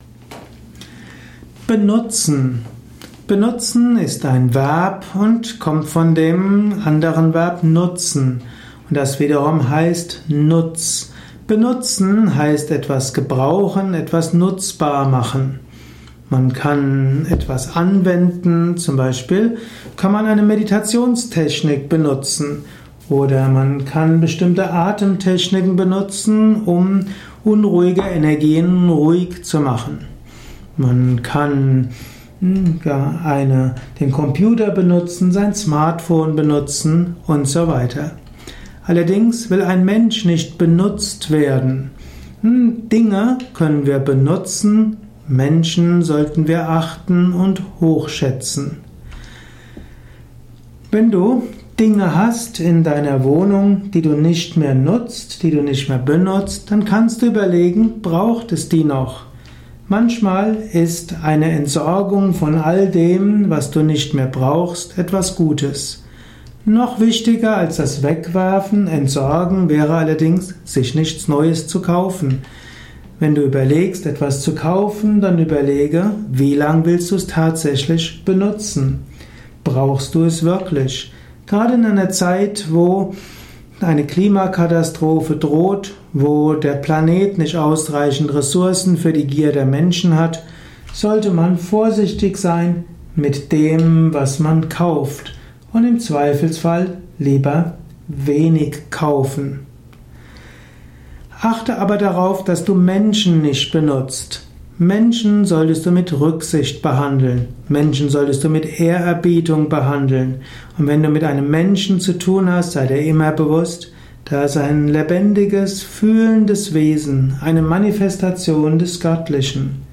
Kurzer Podcast über das Thema Benutzen. Erfahre einiges zum Thema Benutzen in diesem kurzen Vortrag.